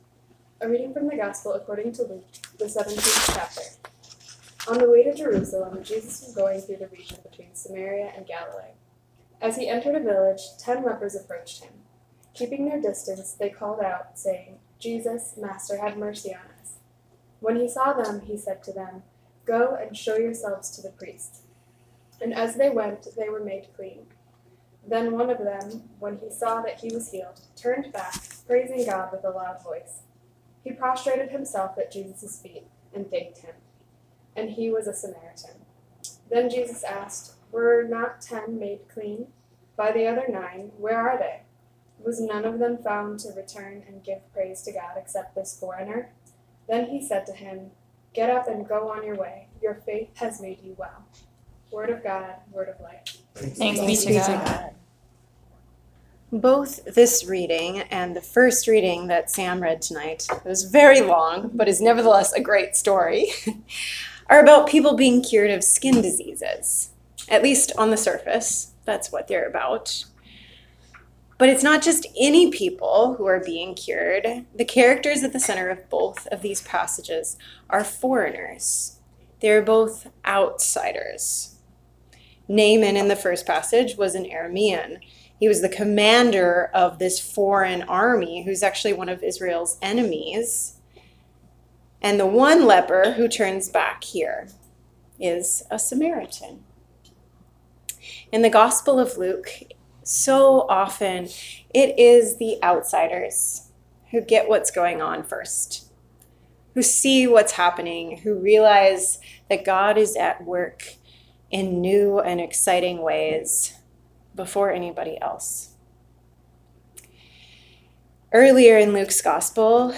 October 14, 2019 Sermon